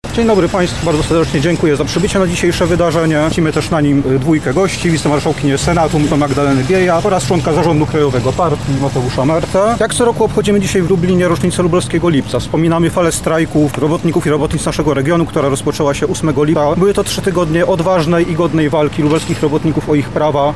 16 lipca o godzinie 11.00 przy pomniku Lubelskiego Lipca, odbyły się obchody upamiętniające strajki polskich robotników, które miały miejsce w 1980 roku.